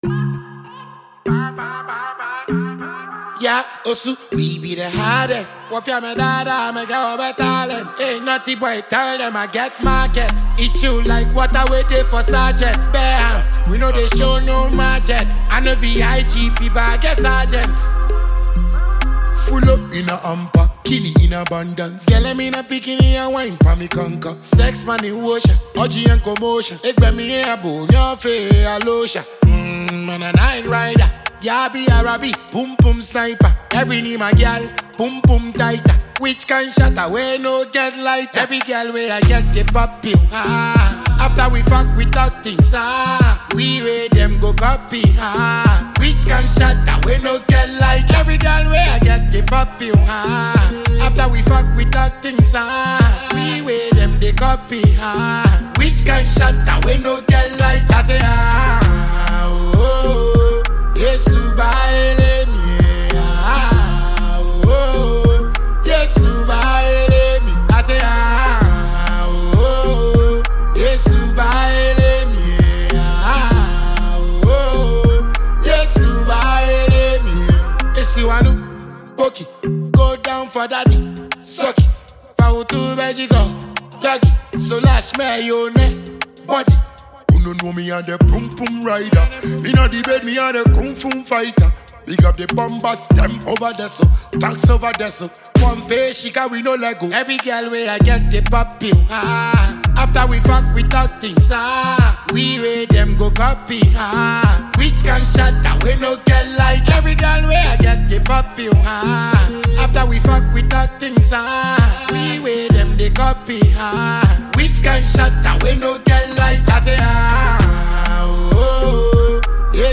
the African Dancehall King from Ghana